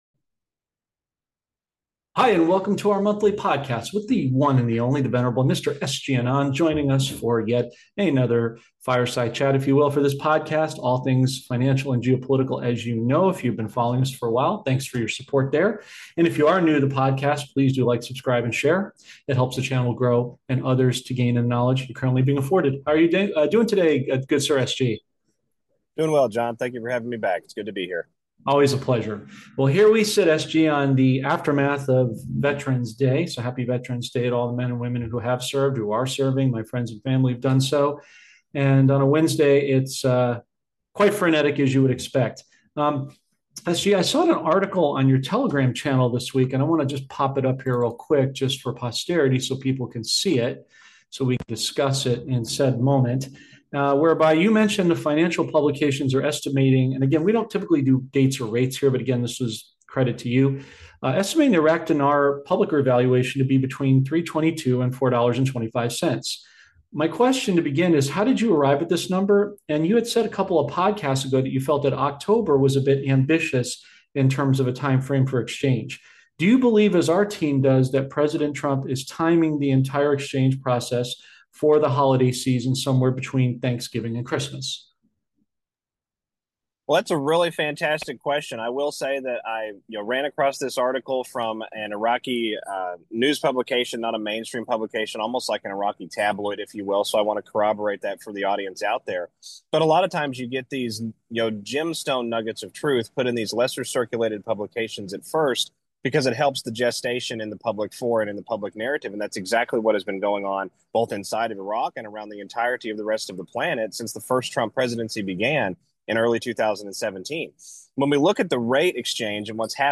This podcast explores big changes in money and world events. The hosts talk about the Iraqi dinar's value possibly changing during the holidays and how this might affect global markets. They also discuss the rise of cryptocurrencies and digital assets, hinting at a major shift in the world economy.